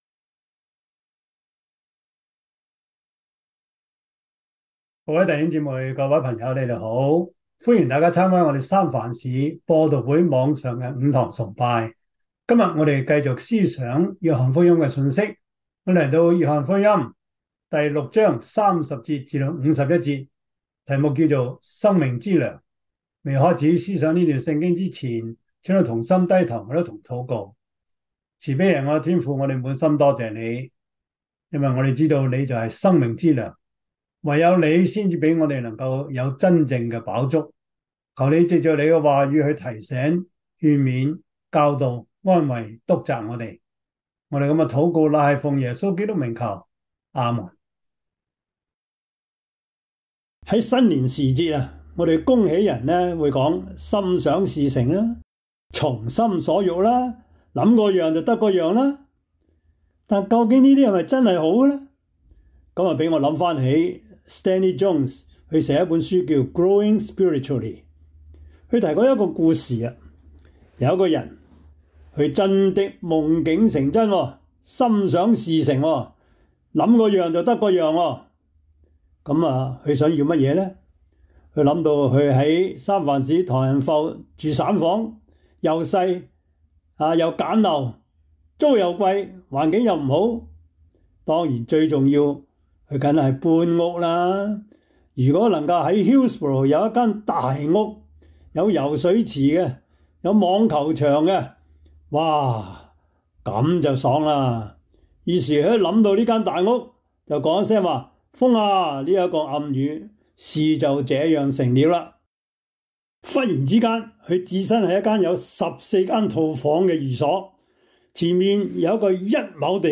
約翰福音 6:30-51 Service Type: 主日崇拜 約翰福音 6:30-51 Chinese Union Version
Topics: 主日證道 « 鐵磨鐵 第三十八課: 跨性別的倫理問題 »